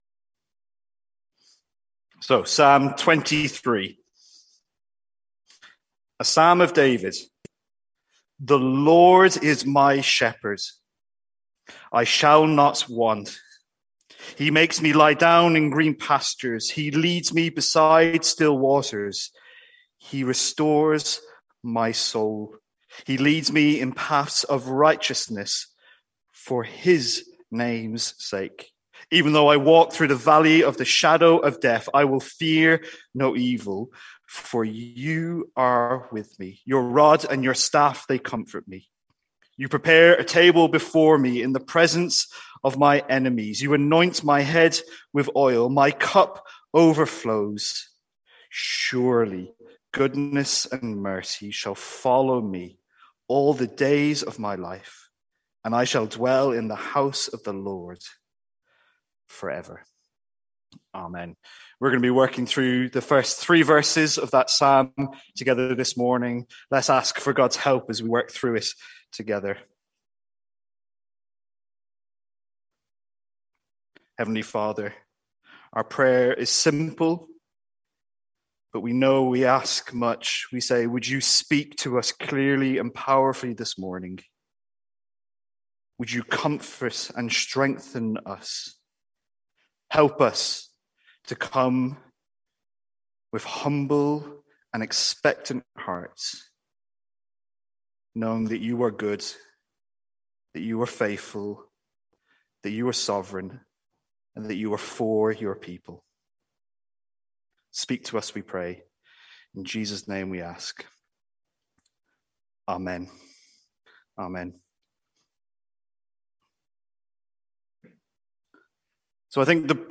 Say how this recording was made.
From our morning series in Psalm 23.